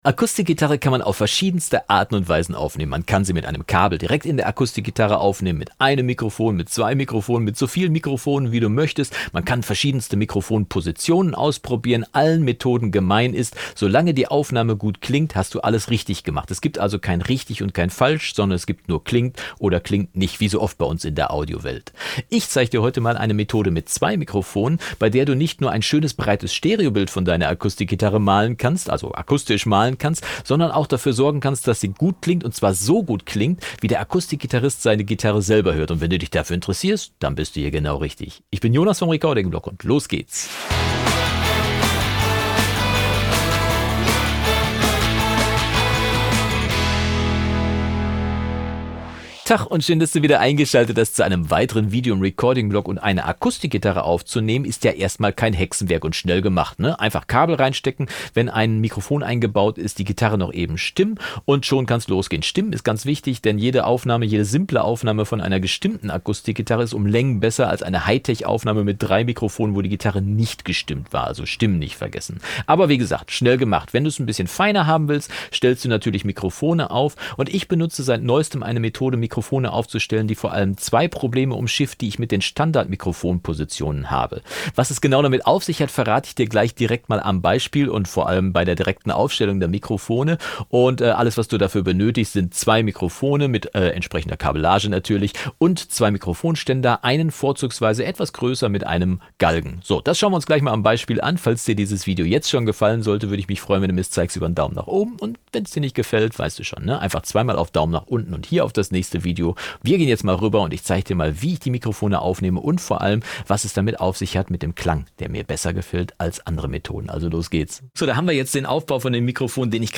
Letzte Episode Top-Sound mit 2 Mikros: Akustik-Gitarre mal anders aufnehmen | Tutorial | Recording-Blog MP116 2.